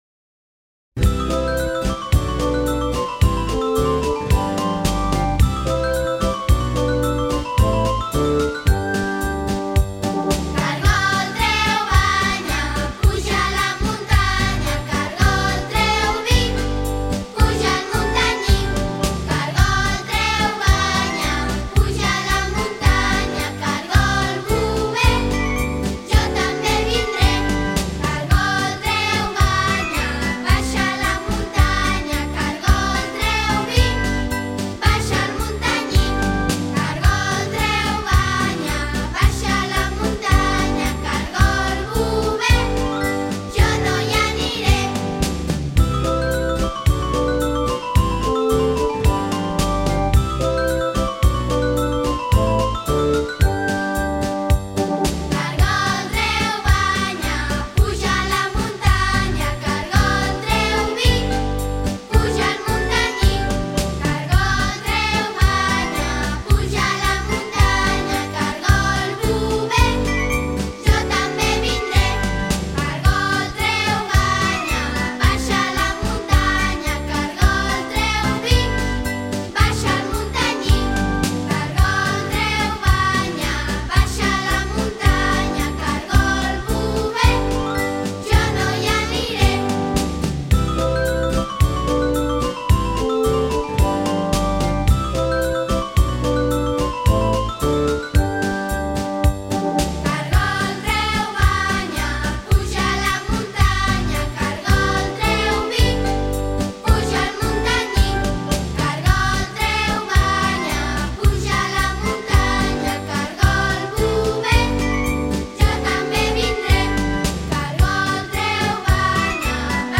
Escolta la cançó cantada per una coral infantil.